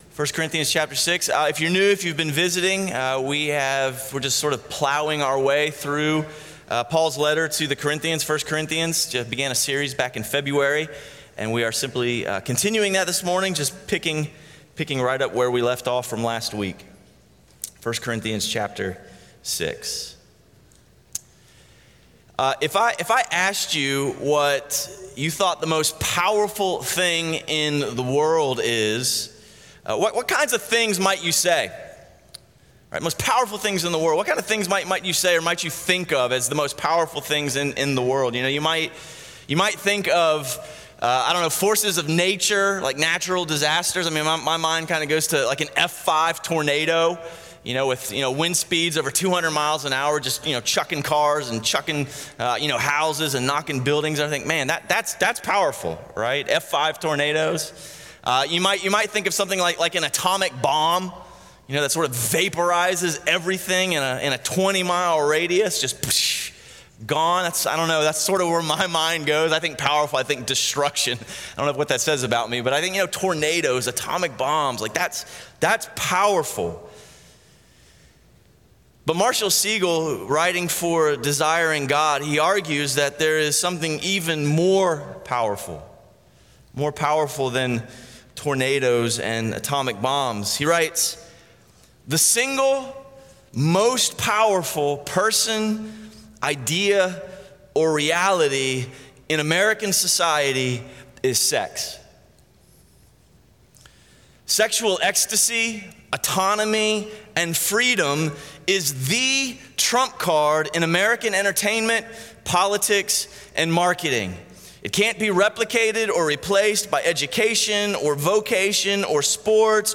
A message from the series "Life Under the Sun."
A Sunday morning series on 1 Corinthians at Crossway Community Church.